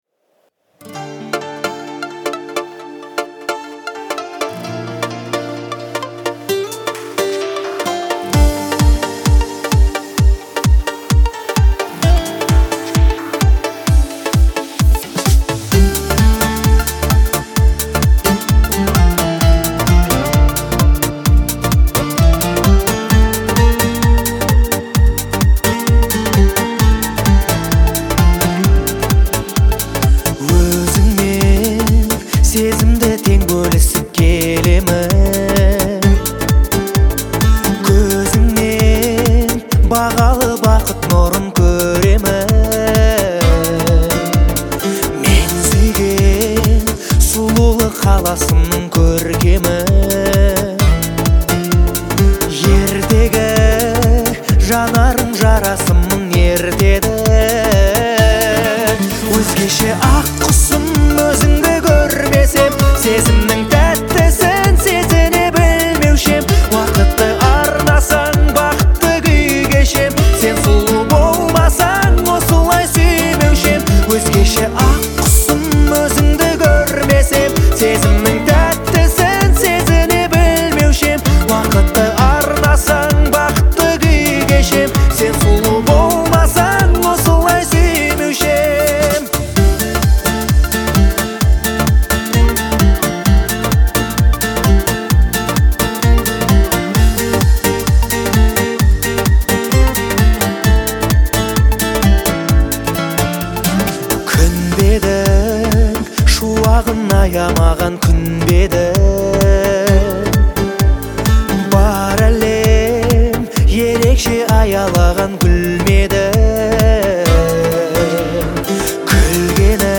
это трогательная песня в жанре казахского поп-фольклора